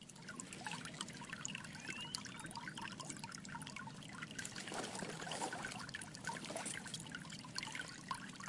自然界 " 溪流长
描述：从我家后面的一条小河里录制的。非常适合背景声音或放松电影
标签： 鸟类 环境 上午 性质 现场录音
声道立体声